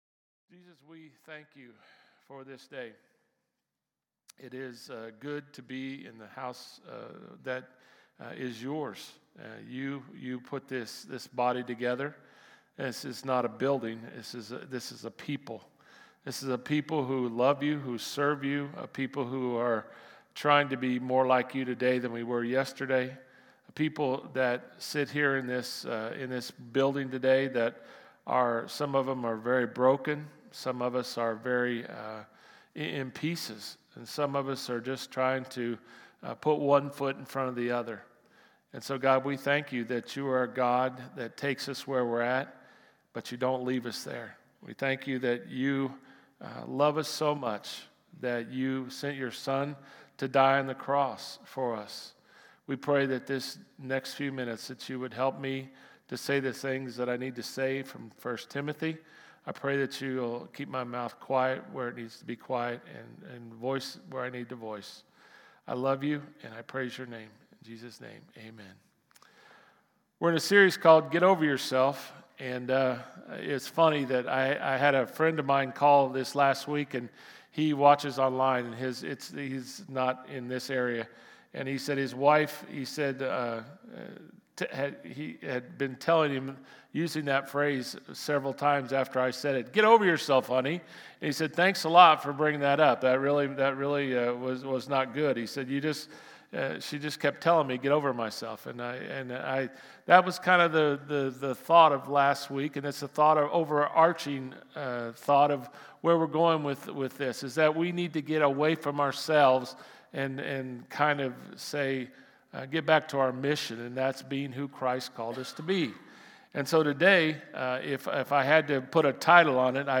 Sermon-5-4-25.mp3